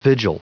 Prononciation du mot : vigil
vigil.wav